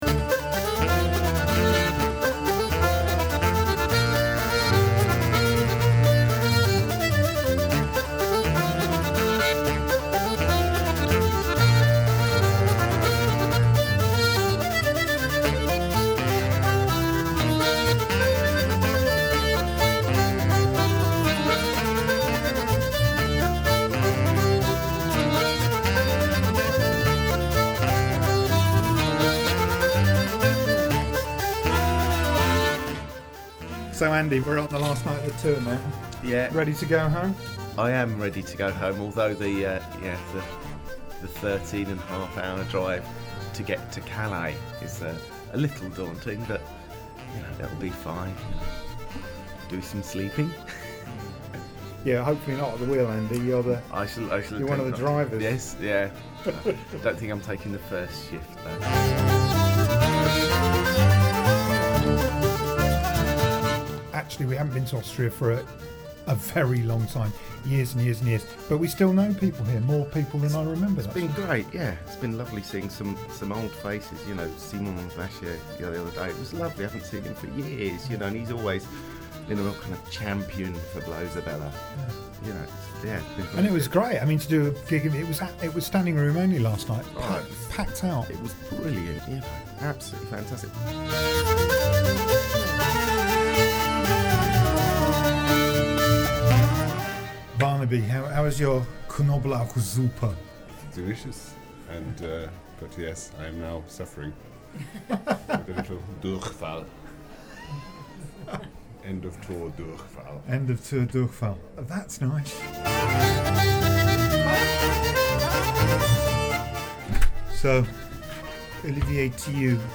Footage of the last gig of the tour
diatonic button accordion.
bagpipes, saxophones.
hurdy-gurdy.
bass guitar.